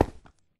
Sound / Minecraft / step / stone6